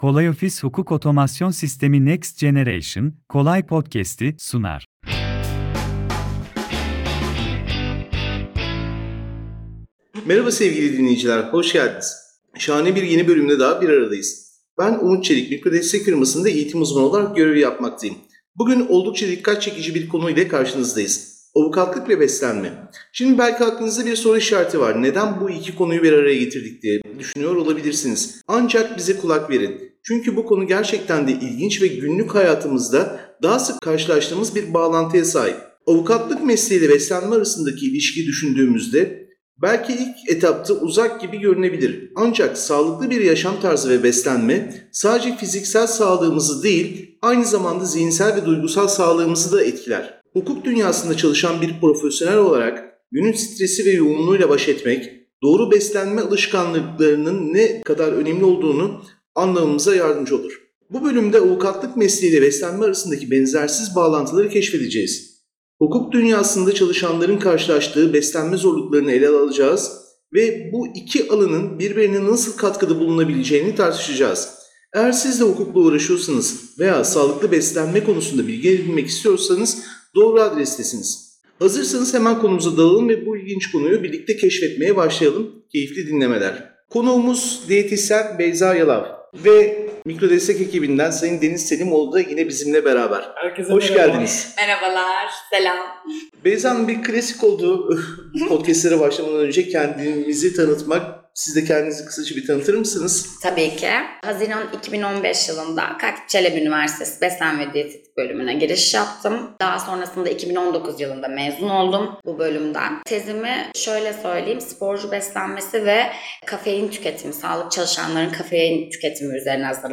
Buyurun bol kahkahalı sohbetimize!